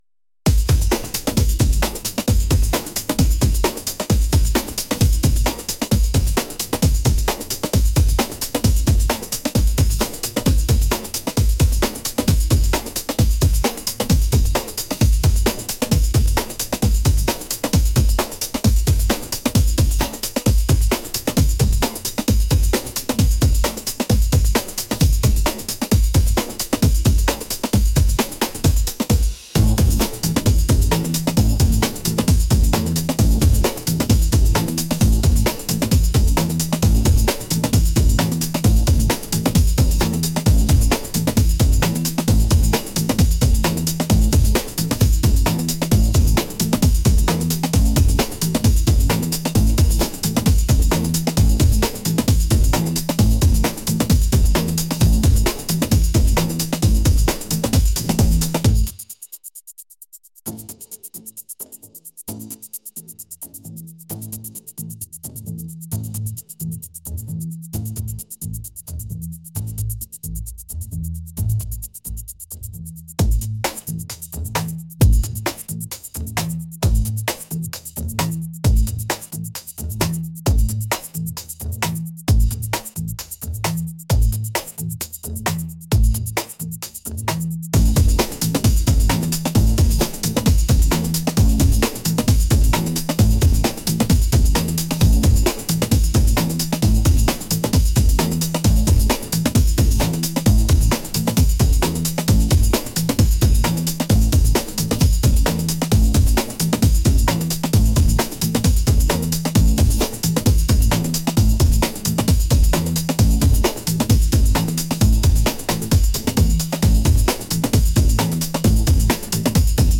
energetic | funky